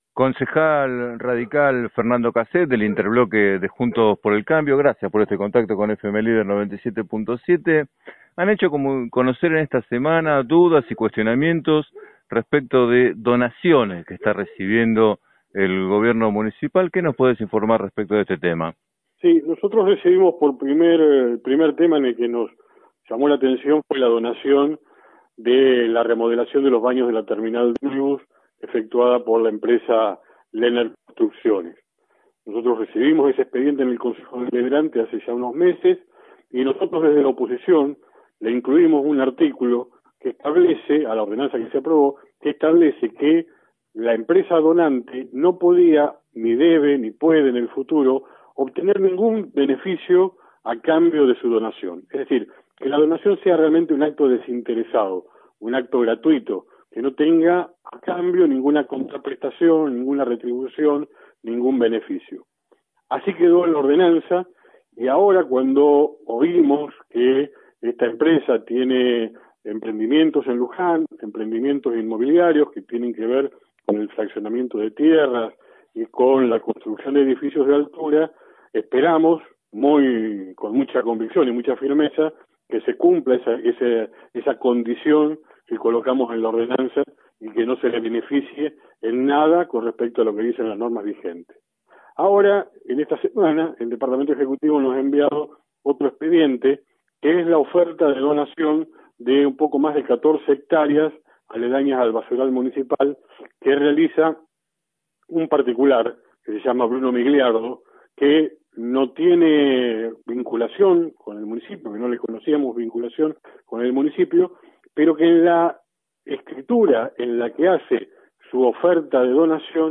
En declaraciones a FM Líder 97.7 el concejal radical Fernando Casset expresó su prevención sobre eventuales modificaciones a indicadores urbanísticos que luego beneficien a los donantes.